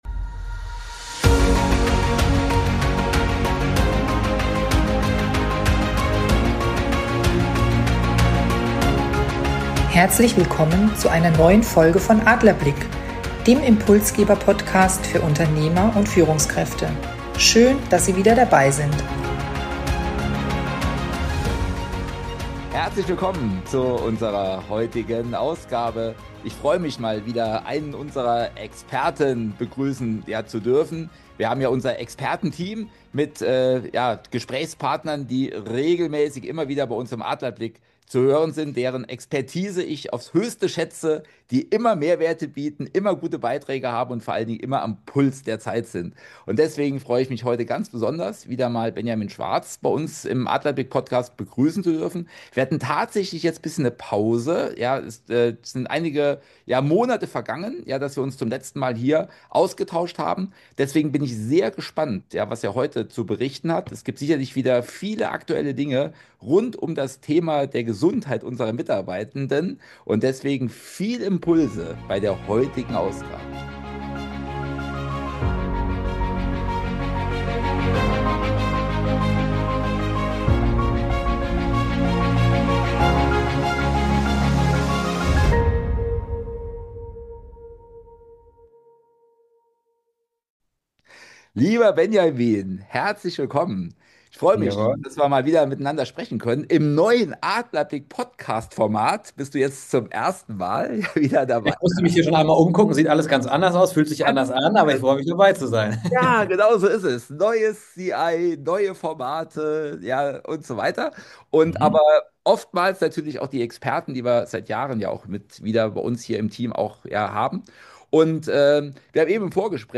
Die beiden sprechen Klartext über wirtschaftliche Auswirkungen, strategische Verantwortung von Führungskräften und die Frage, wie Sie als Unternehmer eine resiliente Organisation aufbauen, die Stabilität, Sinn und Sicherheit vermittelt.